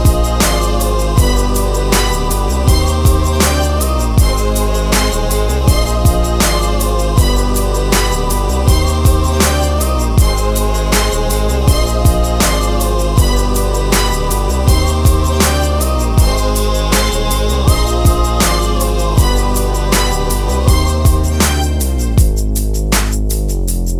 no Backing Vocals R'n'B / Hip Hop 4:02 Buy £1.50